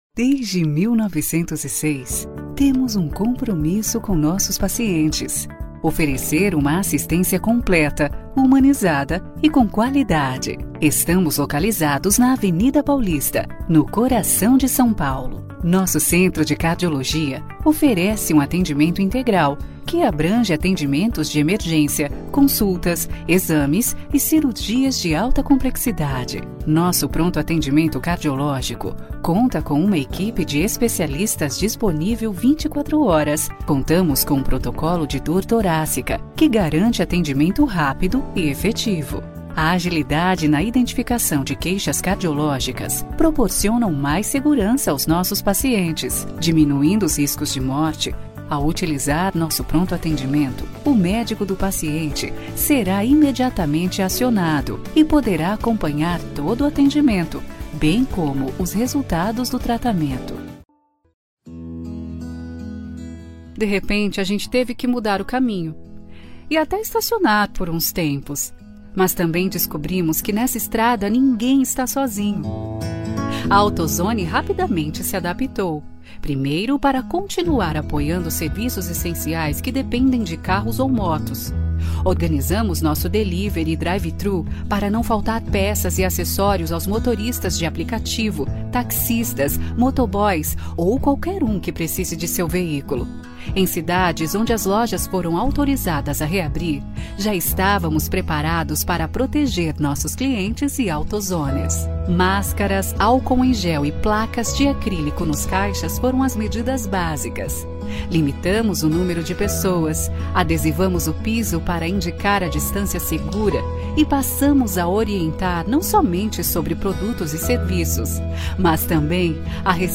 16 years of experience, my interpretation is smooth and pleasant, with a captivating melodic style, bringing credibility and adding value to your brand.
Sprechprobe: Industrie (Muttersprache):
My interpretation is smooth and pleasant, with a catchy melodic style, bringing credibility and adding value to the brands.